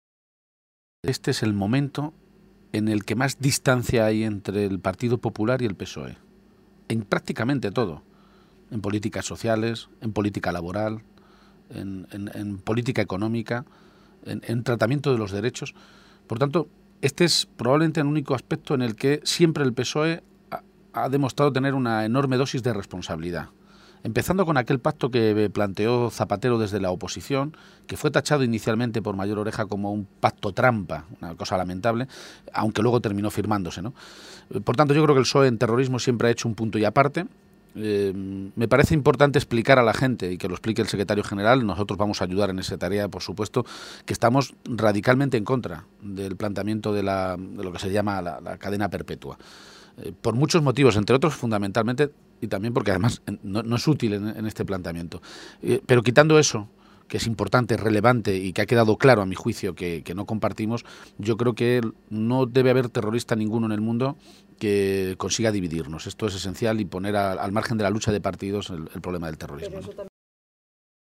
Audio Page-entrevista Ser Tarancon-pacto contra el yihadismo